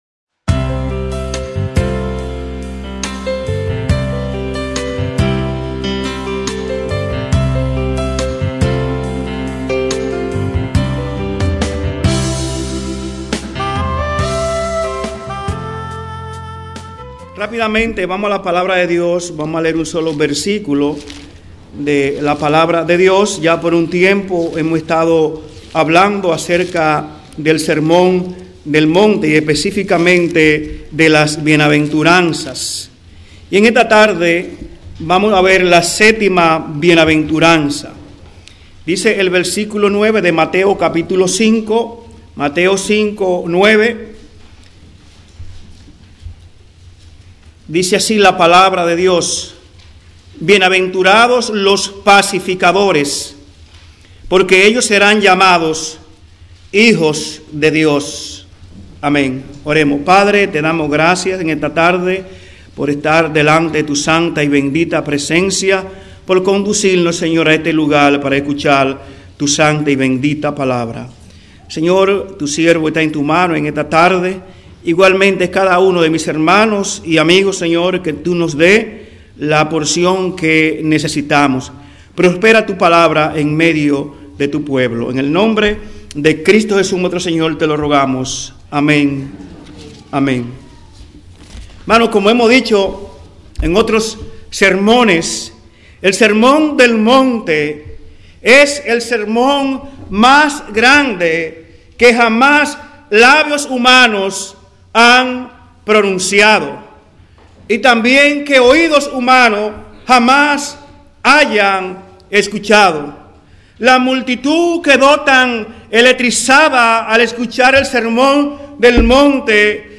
Templo Bíblico Providence